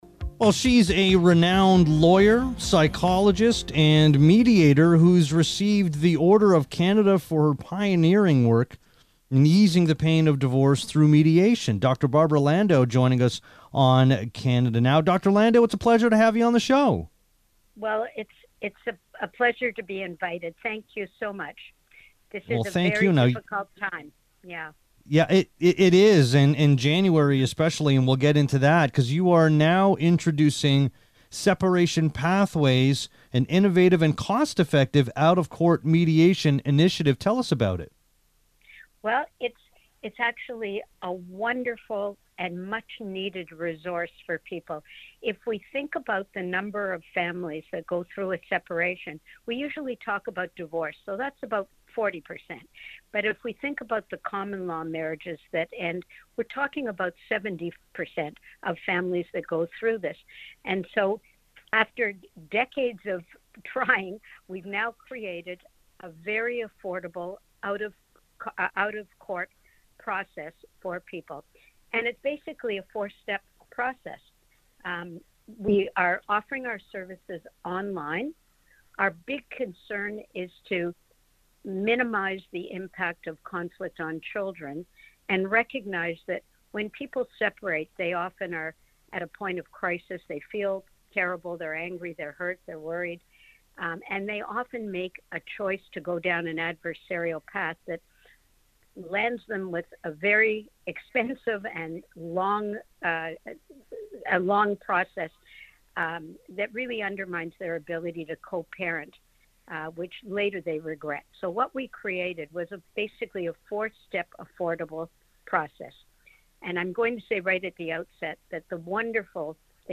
In an exclusive interview